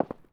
Concret Footstep 02.wav